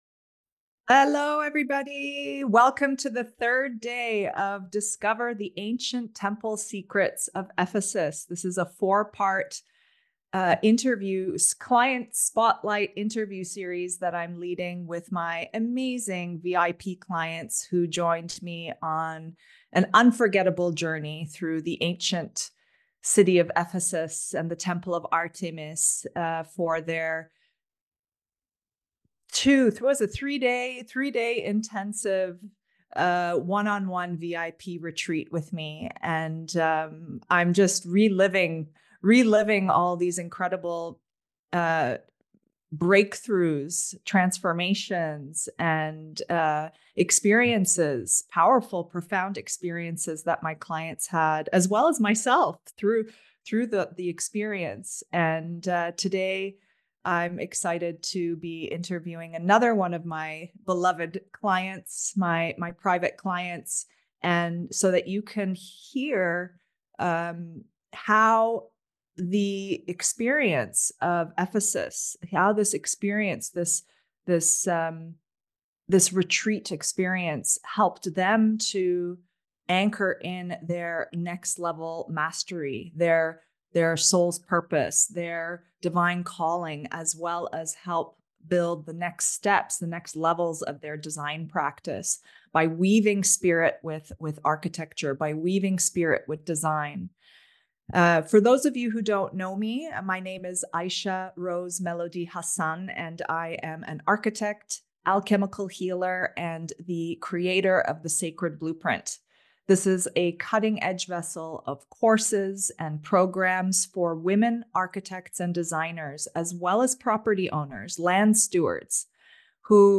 Discover Ancient Temple Secrets of Ephesus [Part 3] ~ VIP Client Spotlight Interview with Architectural Designer